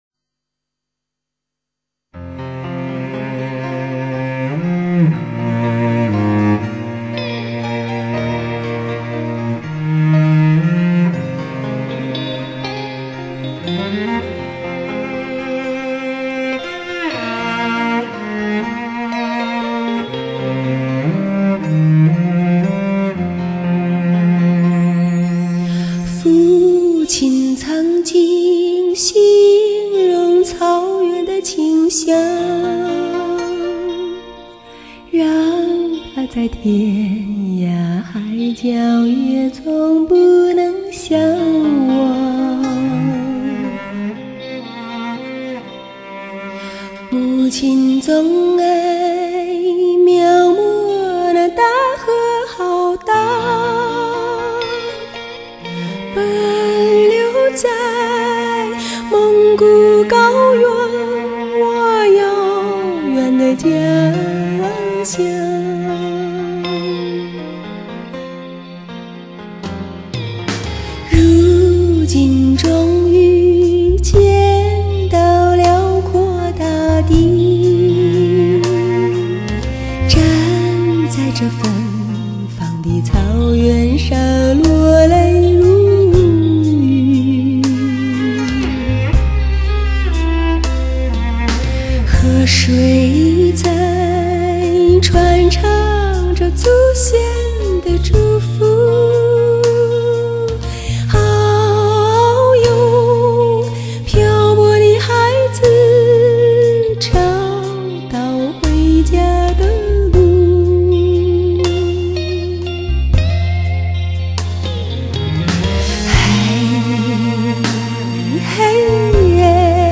空灵而醇美的女声中绽放浓郁的蒙古情怀。原汁原味的原生态民族音乐。
借由高保真立体声DTS多声道环绕技术给你最真实的发烧享受。